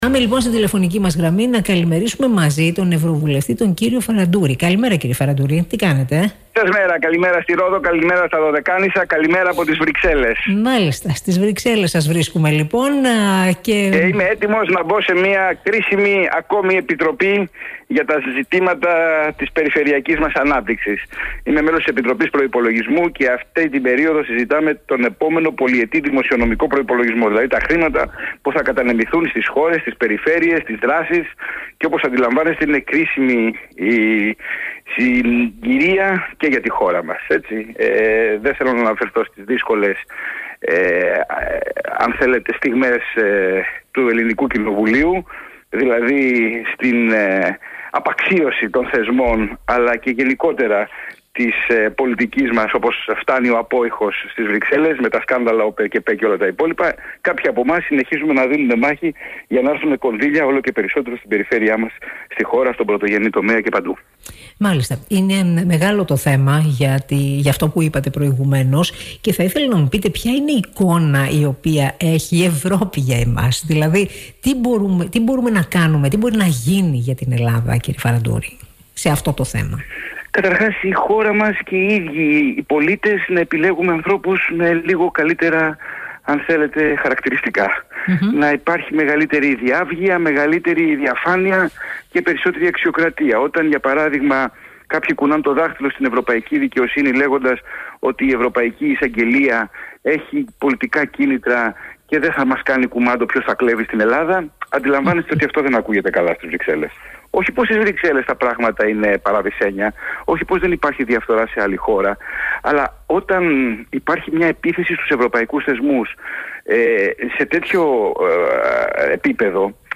Είναι απαράδεκτο να μην λαμβάνει μέτρα στήριξης για τον πρωτογενή τομέα η κυβέρνηση την στιγμή που υπάρχουν πολλοί διαθέσιμοι πόροι από την Ευρωπαϊκή Ένωση, δήλωσε στον Prime 103.7 ο ανεξάρτητος Ευρωβουλευτής και Καθηγητής Ευρωπαϊκού Δικαίου & Δικαίου Ενέργειας στο Πανεπιστήμιο Πειραιώς, Νικόλας Φαραντούρης.